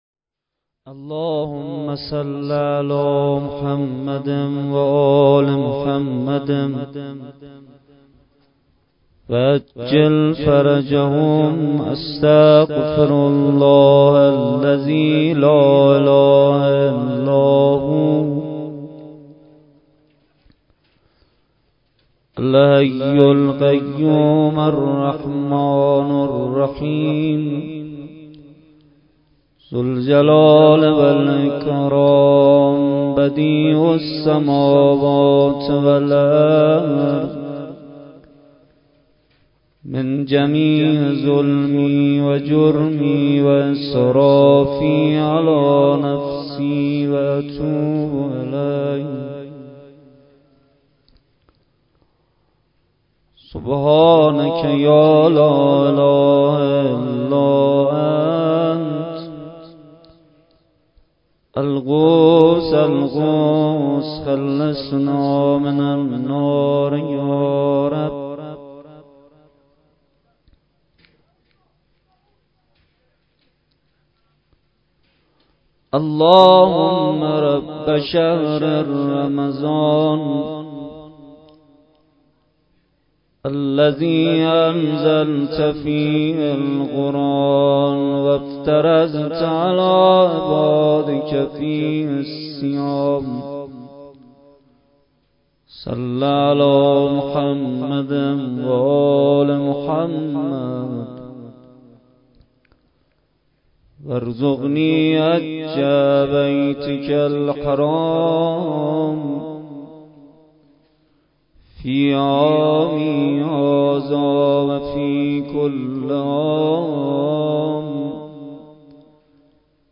شب سیزدهم ماه رمضان با مداحی کربلایی محمدحسین پویانفر در ولنجک – بلوار دانشجو – کهف الشهداء برگزار گردید.
دعا و مناجات روضه لینک کپی شد گزارش خطا پسندها 0 اشتراک گذاری فیسبوک سروش واتس‌اپ لینکدین توییتر تلگرام اشتراک گذاری فیسبوک سروش واتس‌اپ لینکدین توییتر تلگرام